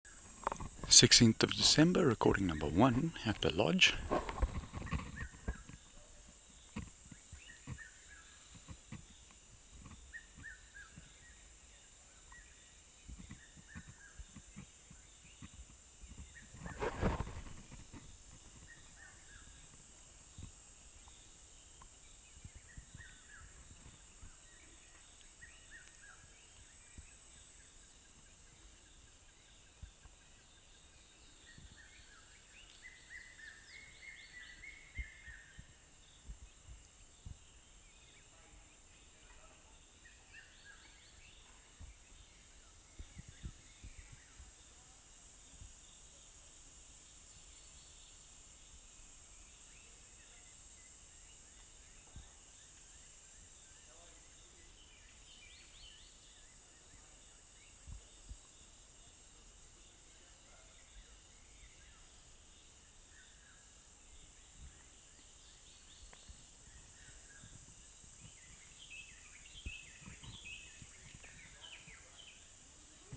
MHV 947 P.cf_.brunea Morningside Ranch #1.wav